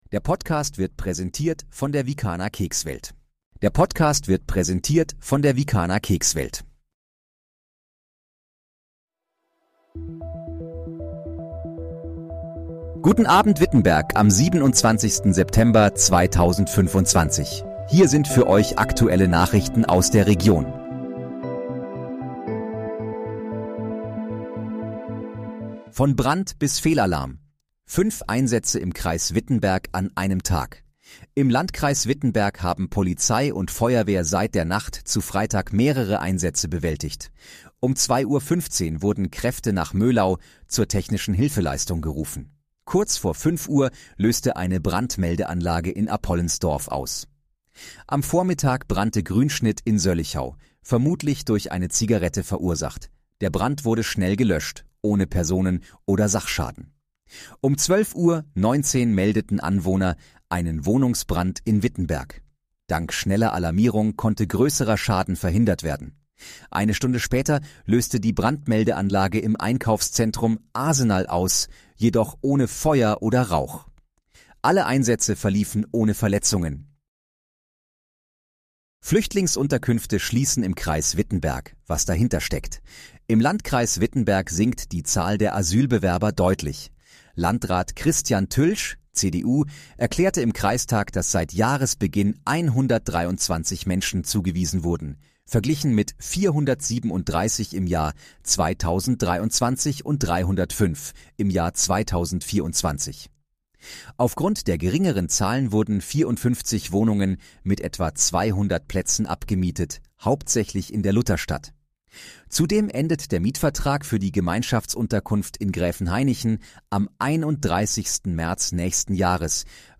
Guten Abend, Wittenberg: Aktuelle Nachrichten vom 27.09.2025, erstellt mit KI-Unterstützung
Nachrichten